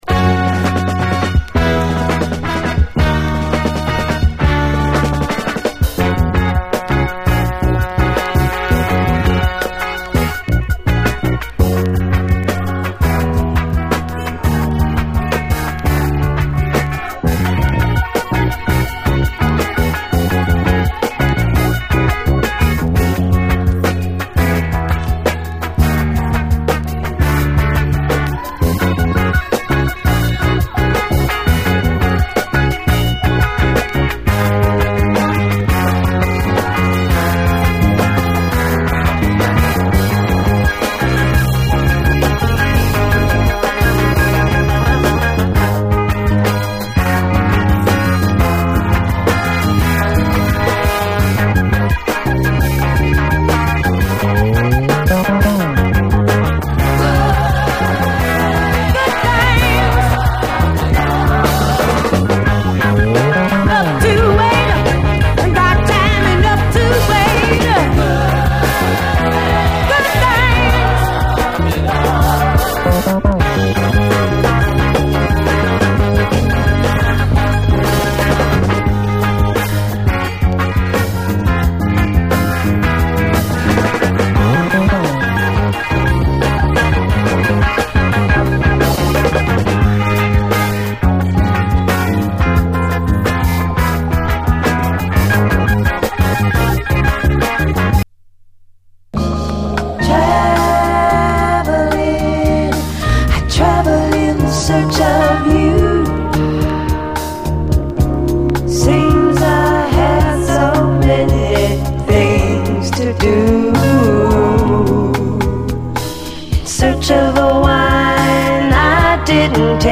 SOUL, 70's～ SOUL, AFRO, WORLD
黒人女性ヴォーカルを擁する70’Sファンク〜ソウル・バンド！
ゴリッとファンキーなビートが魅力。